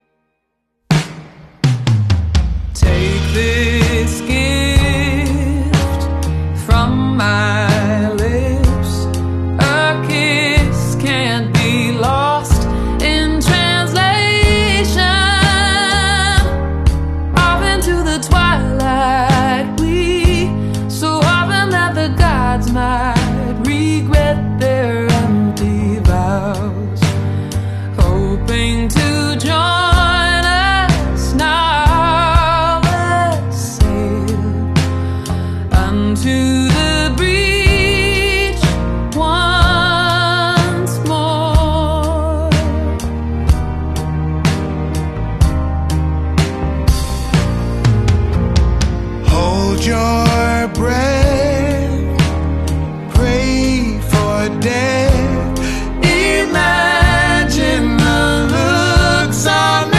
Suddenly, it was a duet and it was an EPIC tale.